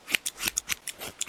haircut3.ogg